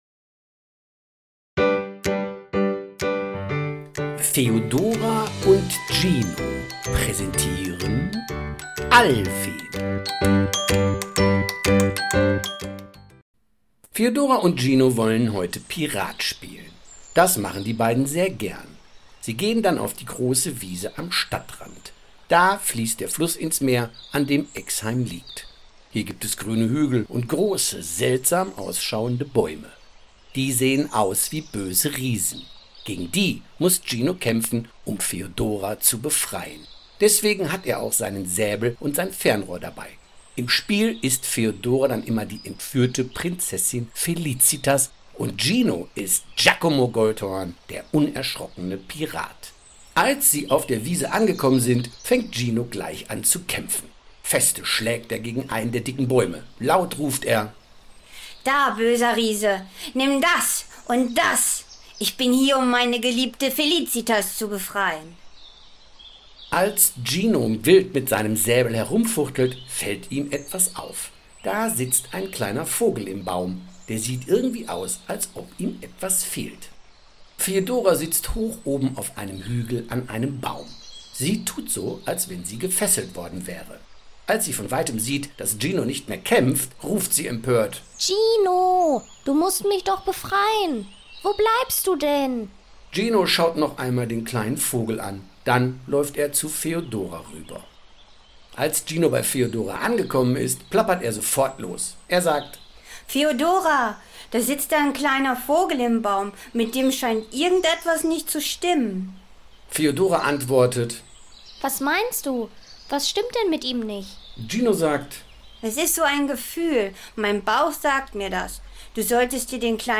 Alwin – The audio play based on the popular picture book!
A lovingly narrated version full of emotion, ideal for falling asleep, relaxing, or simply listening.